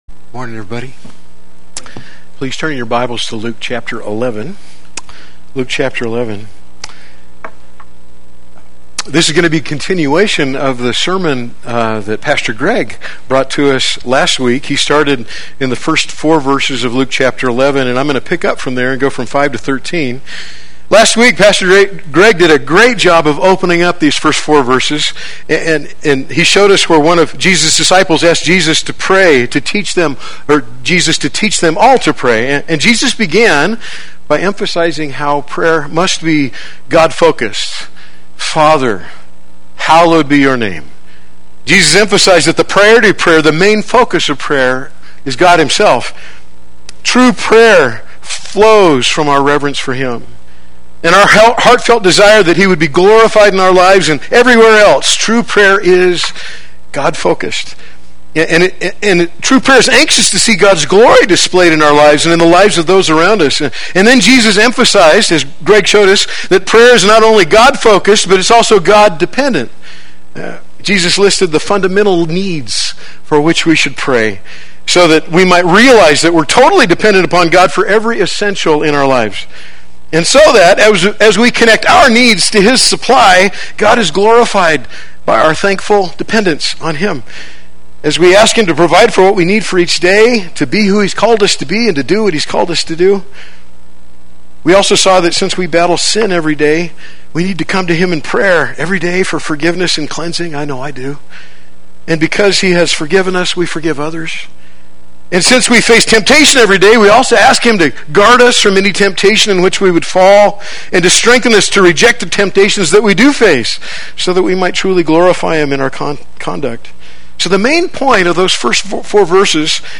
Play Sermon Get HCF Teaching Automatically.
Why we Pray Sunday Worship